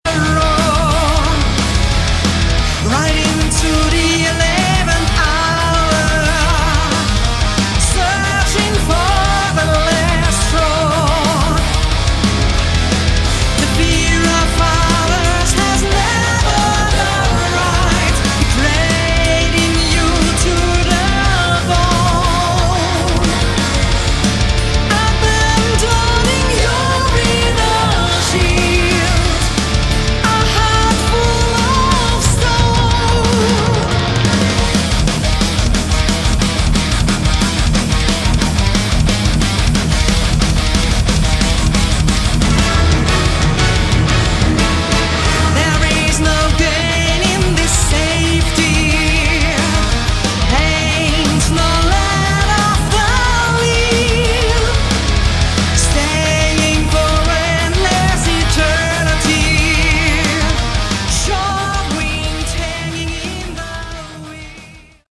Category: Symphonic Metal
lead vocals
drums
bass
lead guitar